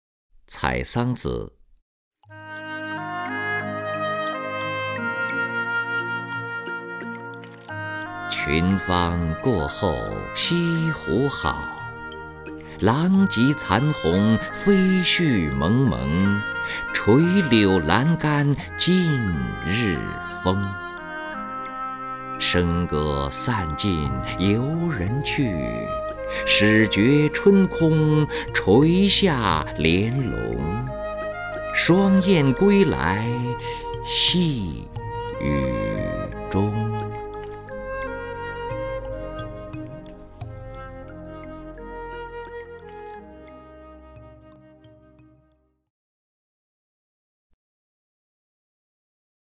任志宏朗诵：《采桑子·群芳过后西湖好》(（北宋）欧阳修)
名家朗诵欣赏 任志宏 目录